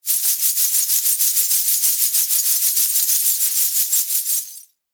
Danza árabe, bailarina haciendo el movimiento twist 04
continuo
moneda
Sonidos: Acciones humanas